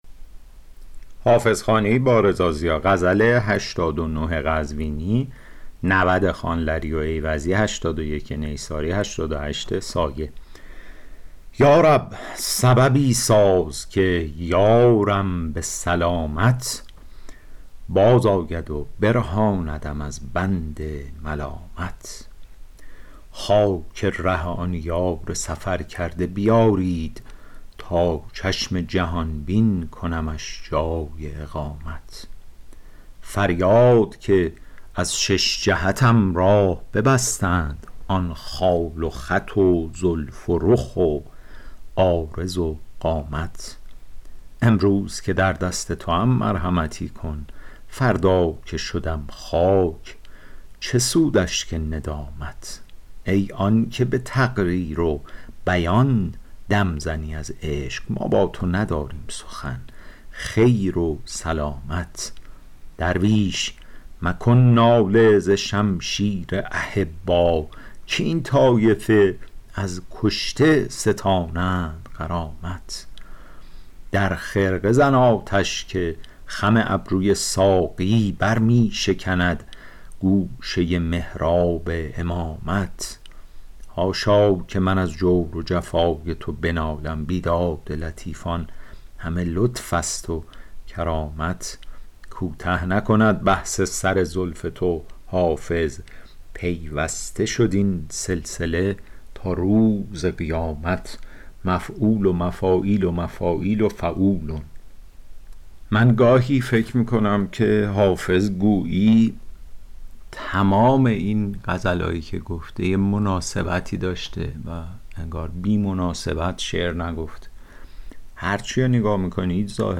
شرح صوتی غزل شمارهٔ ۸۹